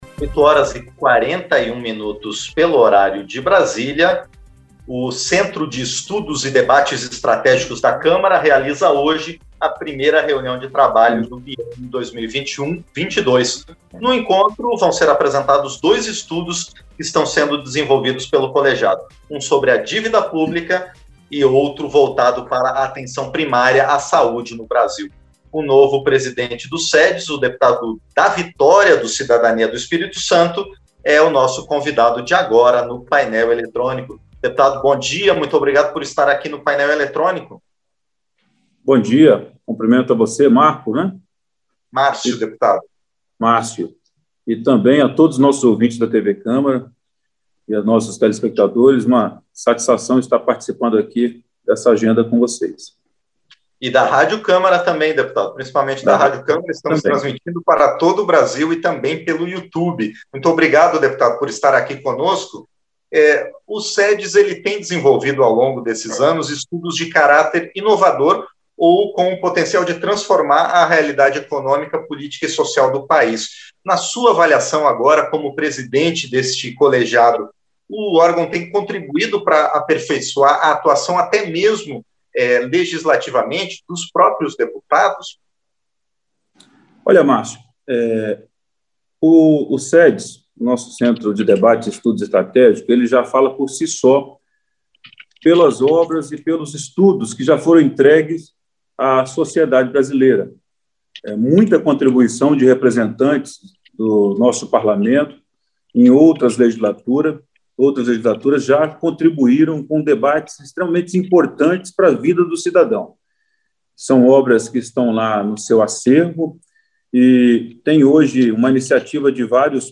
Entrevista - Dep. Da Vitória (CID-ES)